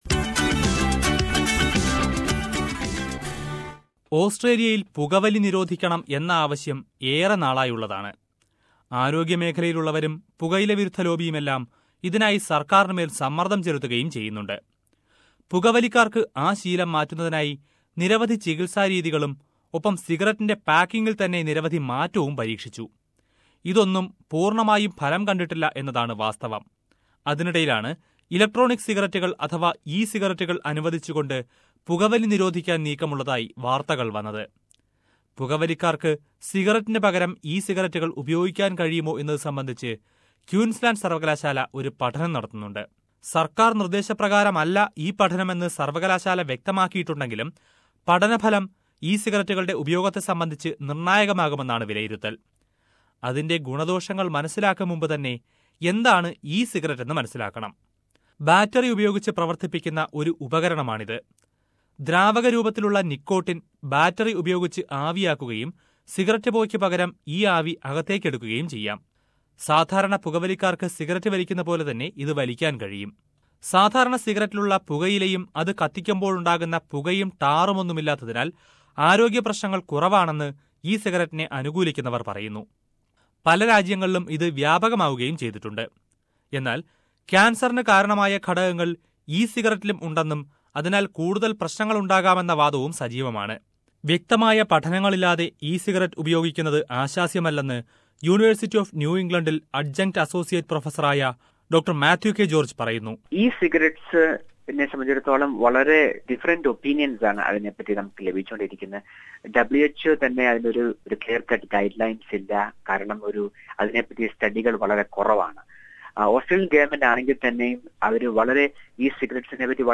But, are there any studies or evidences to prove it? Let us listen to a report..